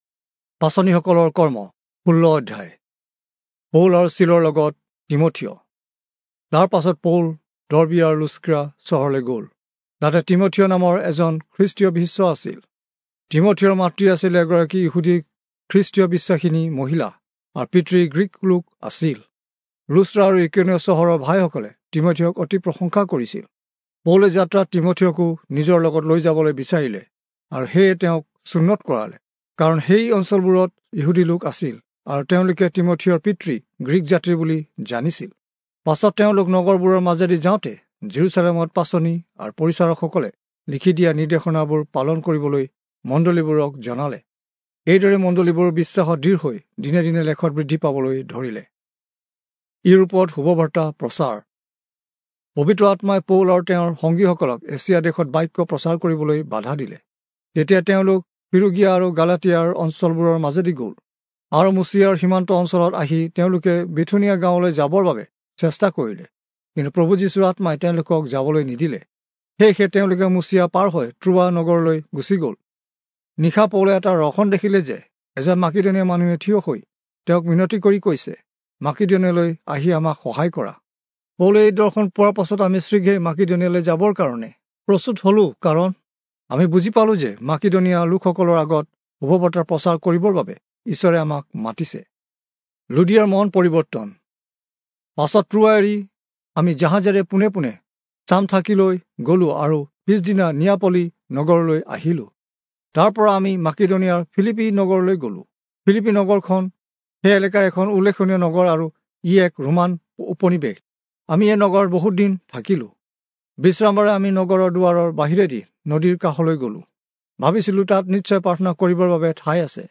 Assamese Audio Bible - Acts 24 in Irvur bible version